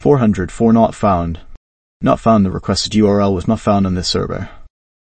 a voice model for